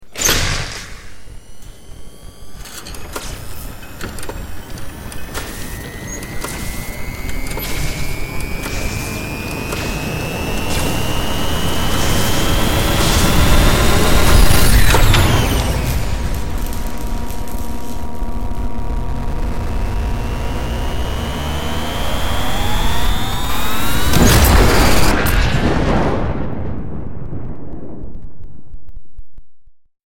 На этой странице собраны звуки работающих реакторов — от глухих гулов до мощных импульсов.
Звук работающего реактора, нарастающая мощность